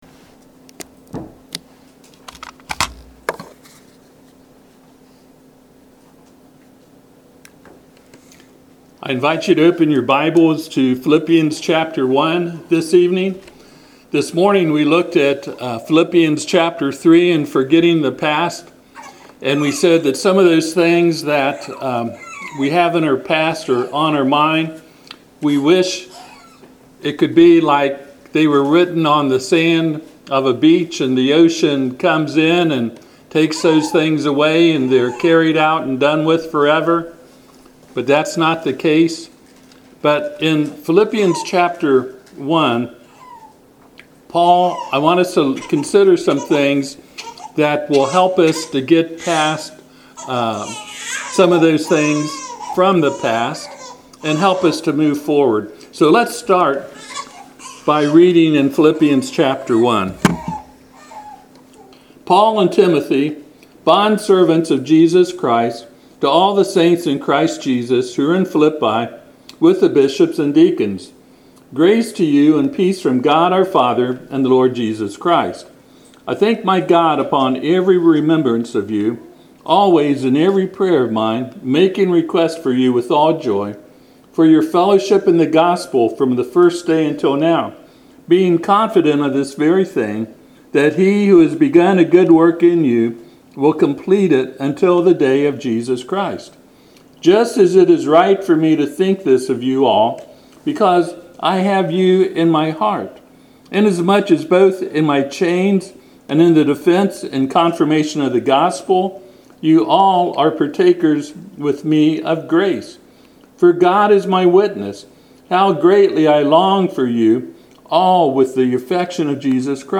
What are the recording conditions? Passage: Philippians 1:1-20 Service Type: Sunday PM